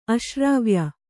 ♪ aśrāvya